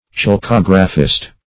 Chalcographist \Chal*cog"ra*phist\, n.
chalcographist.mp3